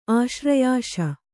♪ āśrayaśa